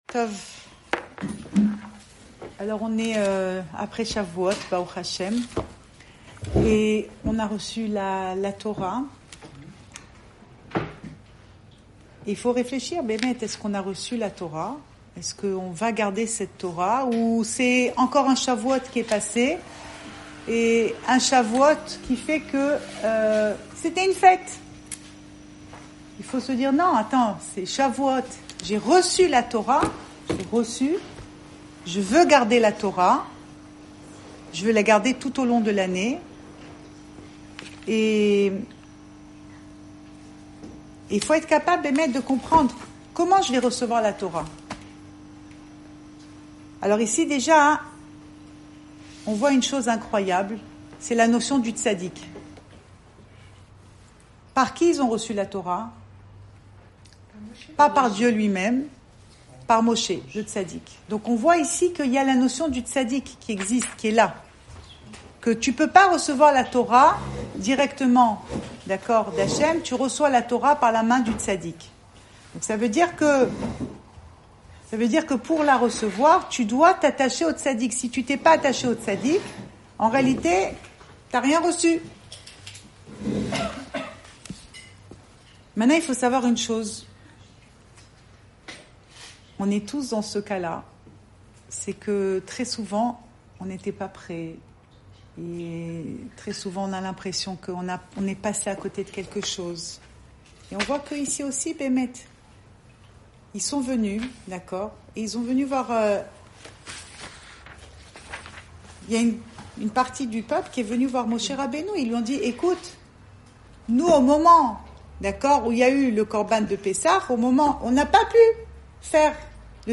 Cours audio Emouna Le coin des femmes Pensée Breslev - 8 juin 2022 9 juin 2022 L’after Chavouot. Enregistré à Tel Aviv